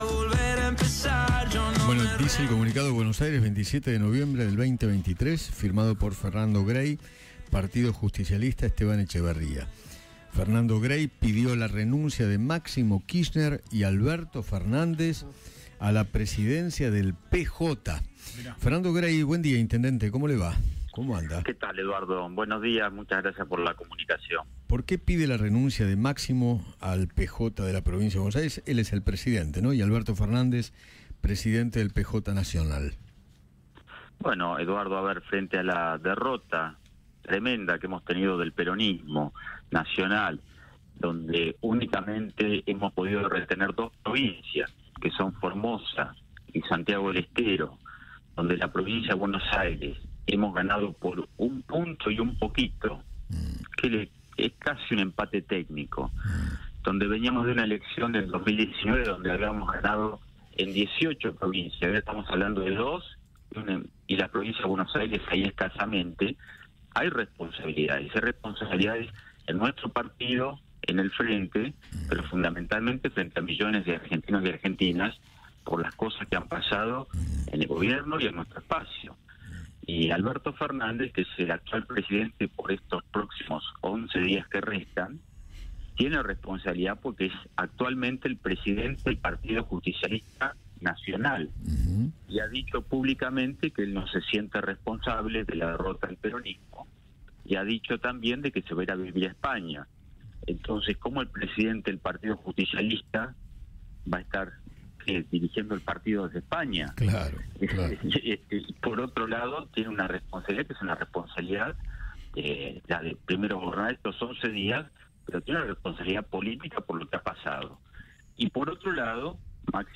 Fernando Gray, intendente de Esteban Echeverría, conversó con Eduardo Feinmann sobre la gestión de Alberto Fernández.